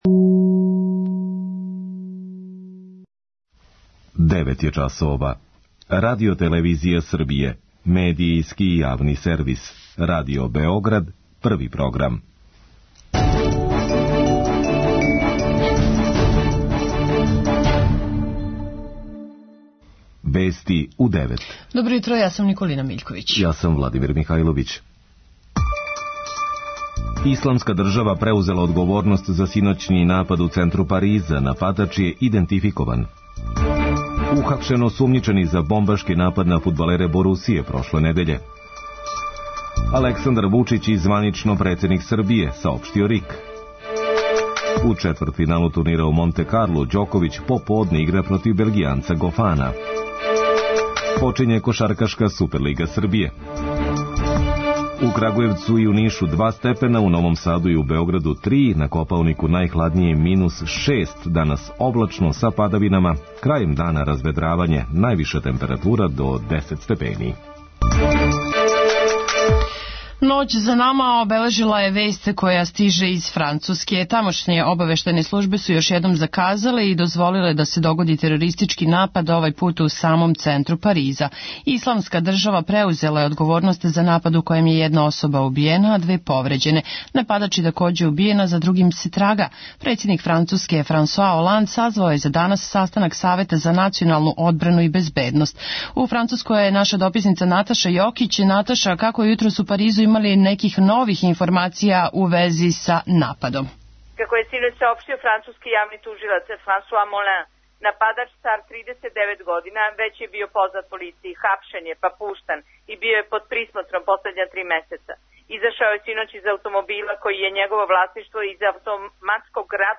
преузми : 3.80 MB Вести у 9 Autor: разни аутори Преглед најважнијиx информација из земље из света.